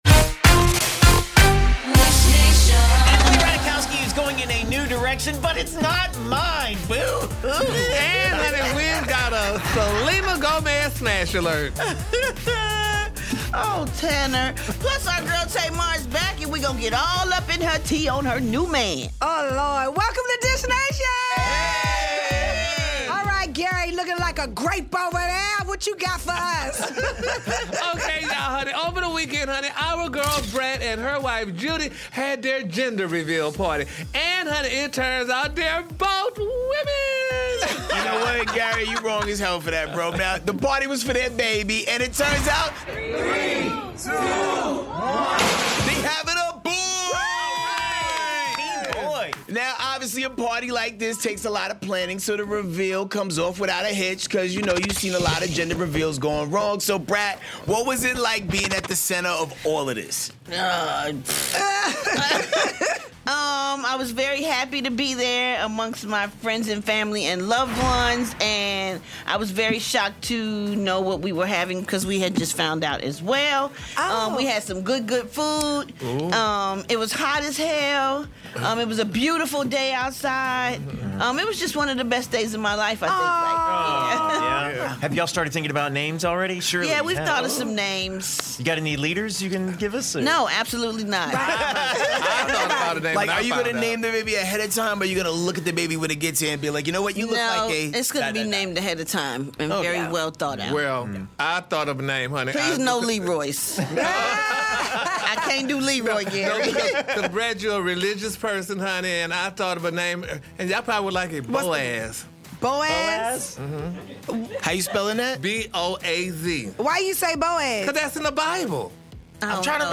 Plus, Tamar Braxton is back in studio and she's talking about her engagement on 'Queens Court' and if she wants to have a big wedding and more kids!